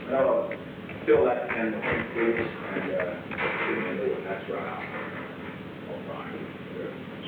Secret White House Tapes
Conversation No. 442-47
Location: Executive Office Building